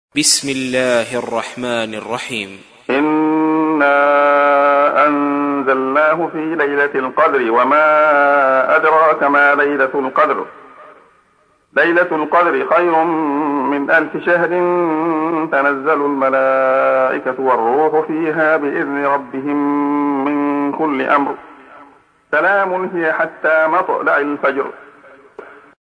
تحميل : 97. سورة القدر / القارئ عبد الله خياط / القرآن الكريم / موقع يا حسين